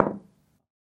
На этой странице собраны звуки бильярда: от четких ударов кием до глухого стука шаров и их падения в лузу.
Бильярдный удар звук слушать